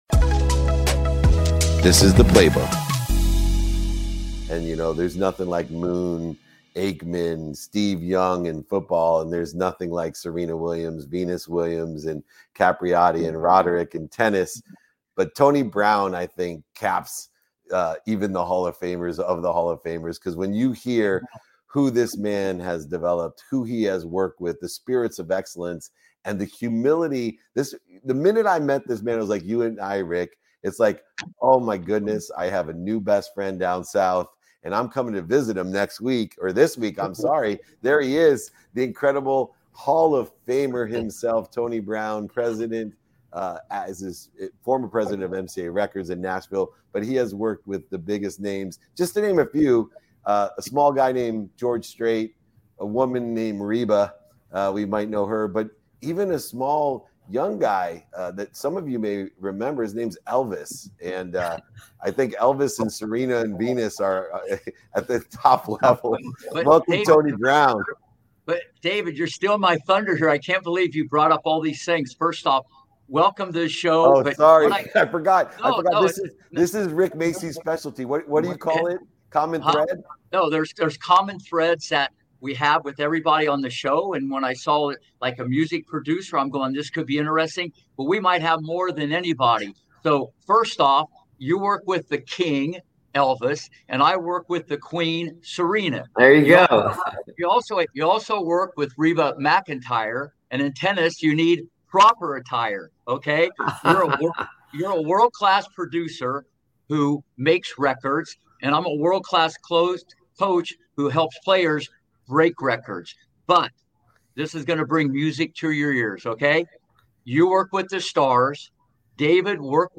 On today’s Game, Set, Life episode with renowned tennis coach Rick Macci, we had the privilege of sitting down with legendary country musician and producer Tony Brown. Drawing from his illustrious career, Tony shared his perspectives on how stars like George Strait and Reba McEntire managed to stay grounded despite their wealth and fame, his cherished memories of performing with Elvis Presley, and the defining moment of his life.